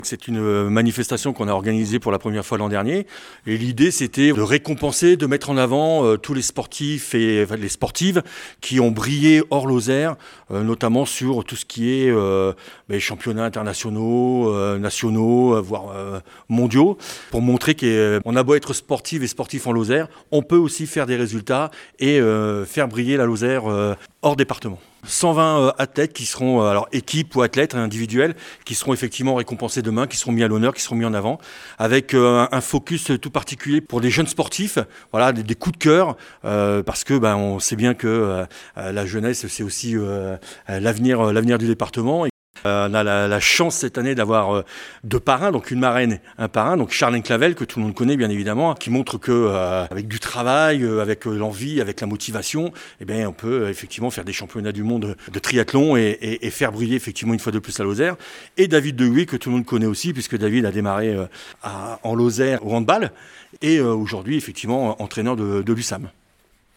Près de 120 athlètes et équipes vont être récompensés pour leurs performances lors de compétitions nationales et internationales. François Robin, conseiller départemental en charge des sports, nous explique l’esprit de cette manifestation.